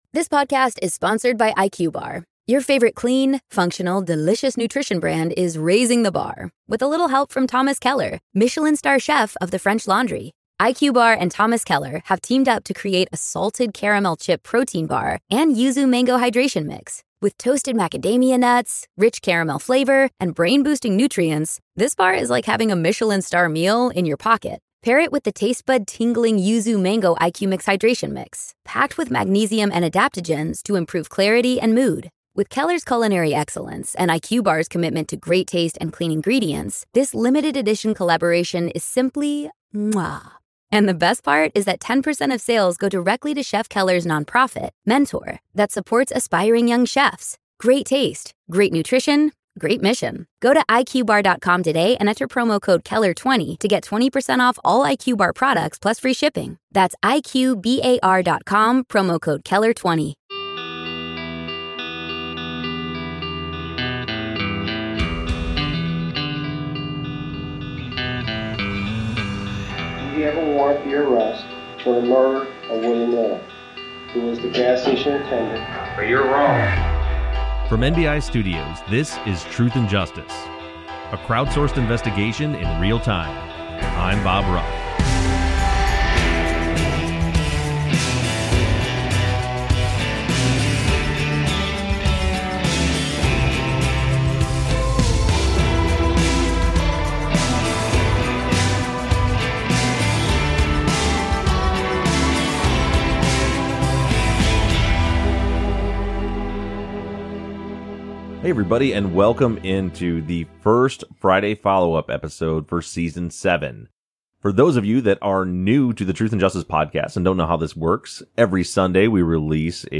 into the studio for another discussion as they address listener questions after the launch of Season 7.